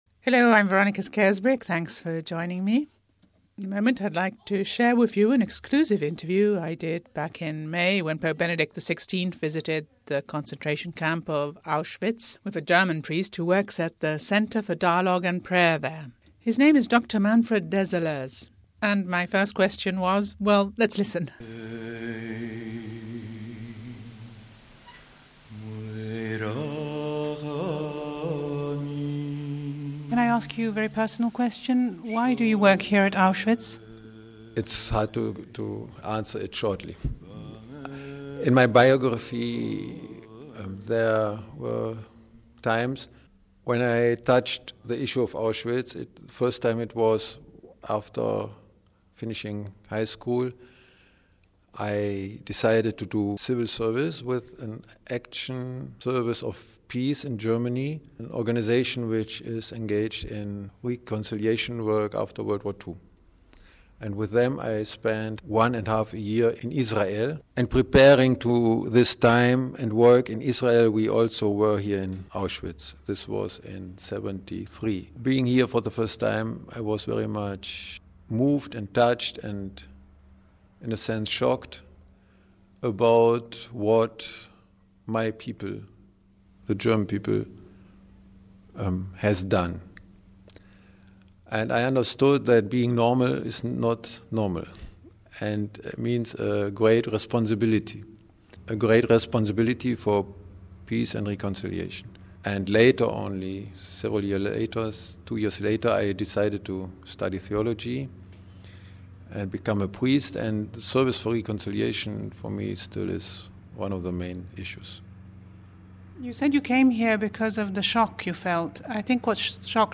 An exclusive interview